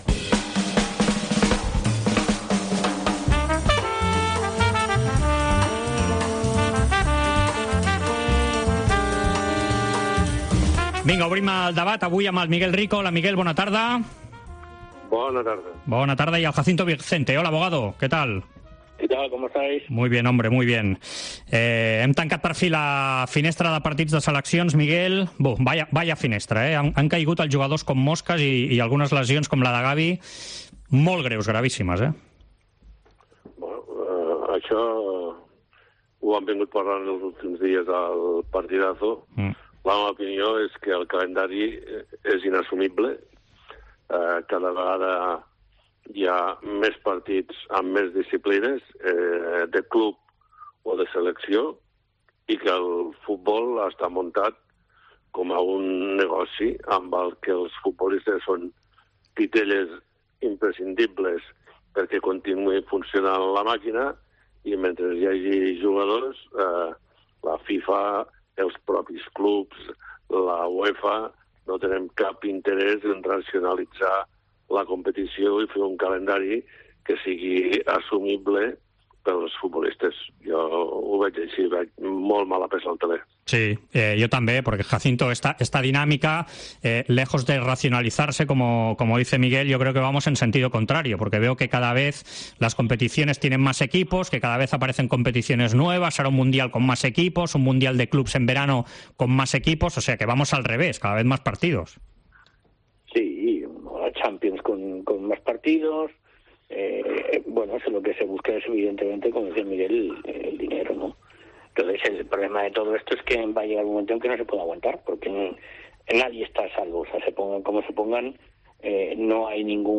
AUDIO: Els dos col·laboradors de la Cadena COPE repassen l'actualitat esportiva de la setmana.